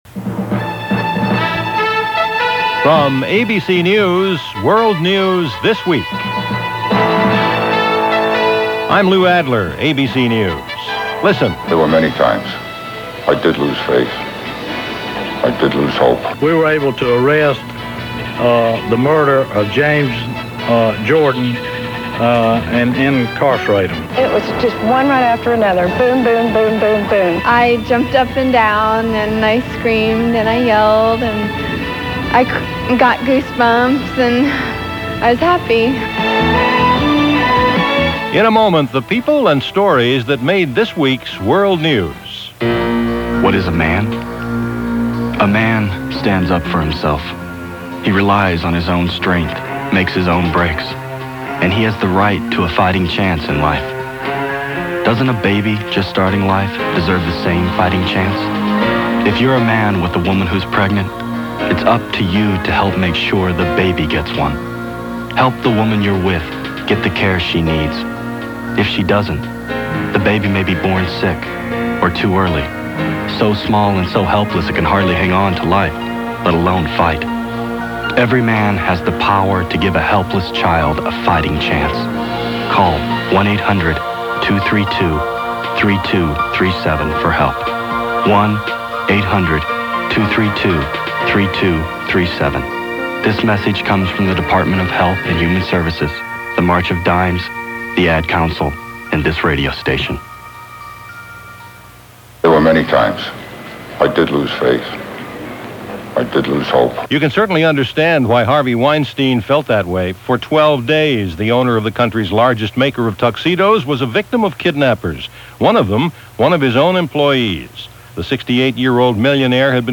A Kidnapping In Queens - Apprehending Killers - Abortion Clinic Targeted - August 22, 1993 - ABC World News This Week.